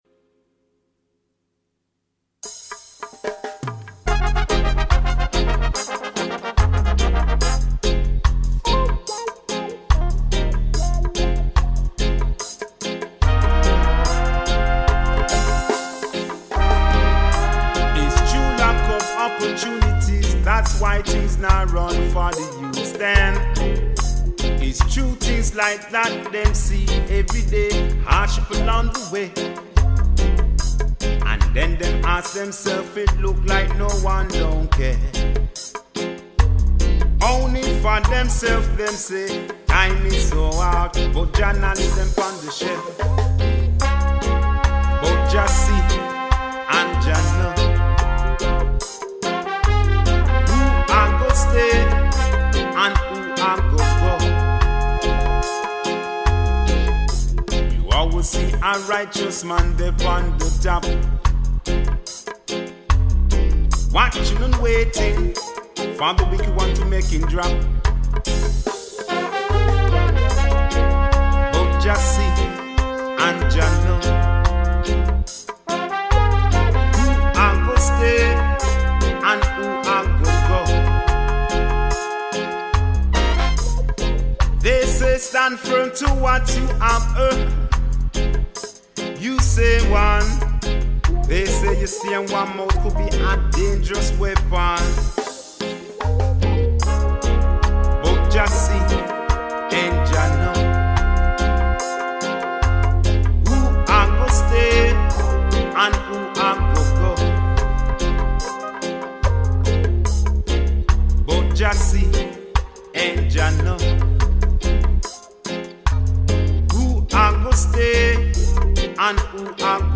guitars
brass section
raw mix